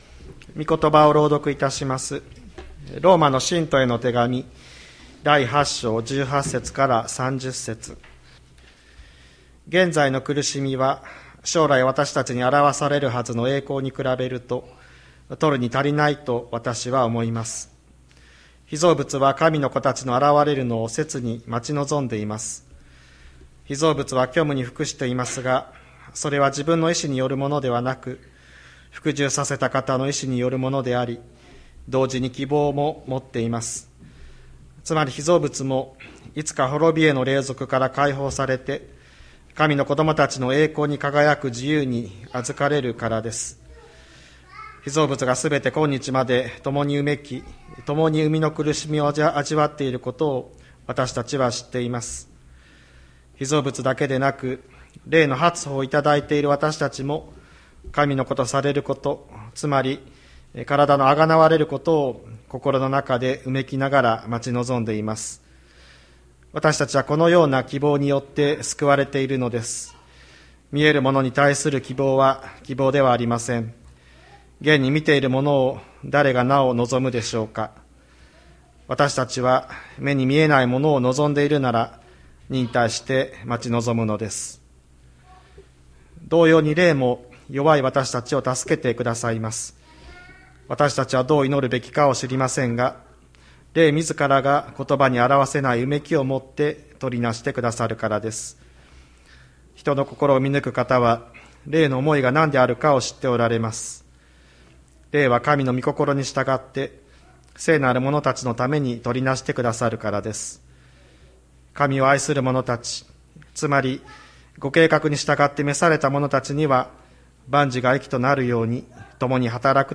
2023年03月12日朝の礼拝「うめくこと、望みに生きること」吹田市千里山のキリスト教会
千里山教会 2023年03月12日の礼拝メッセージ。